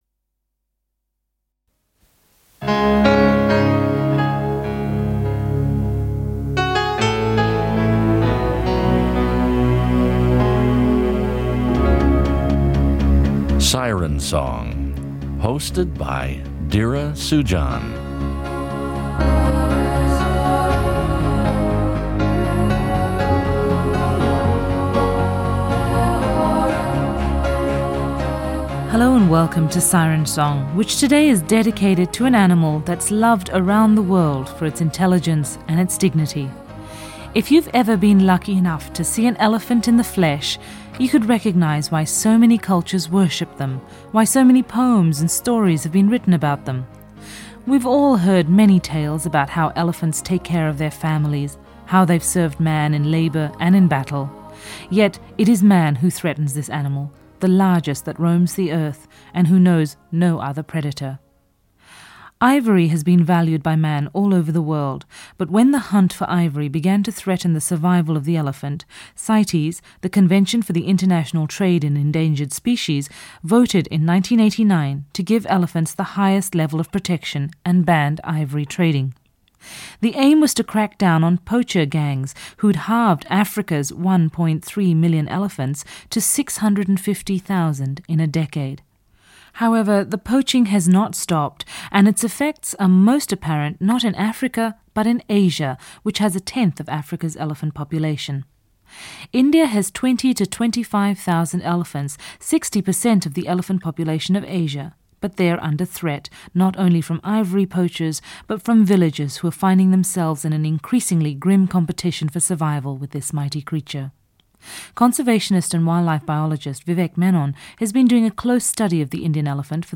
two Indian conservationists who are worried about the plight of the Asian elephant. There are around 25,000 of them in India, but ivory poachers and a shrinking habitat are a real threat to their survival.